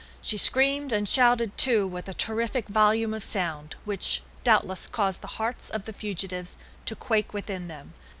this link, corresponding to a male speech signal from Librispeech corpus.
This signal has 131,200 samples and a sample rate of 16,000 Hz.